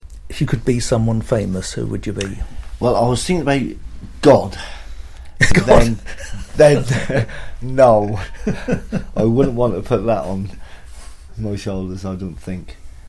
Interview Outtake